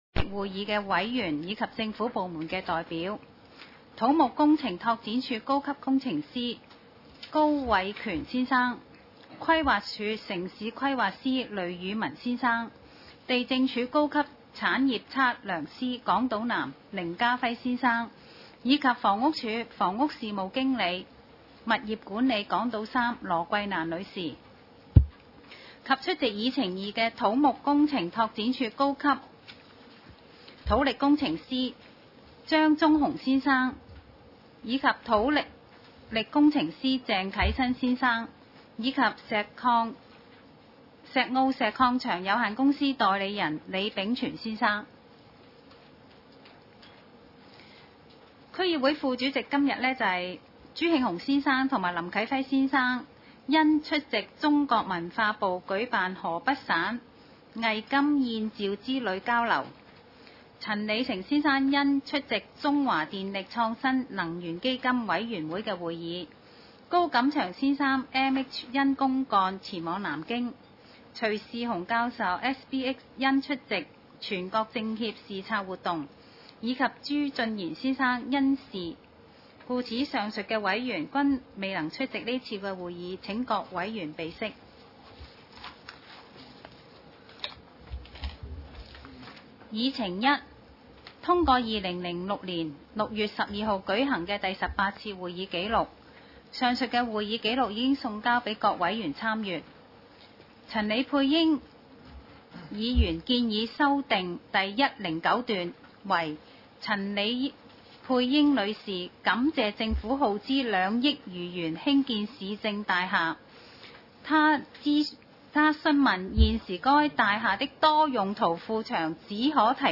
Agenda for the 19th Meeting of the
地點  ﹕ 香港 Venue : SDC Conference Room,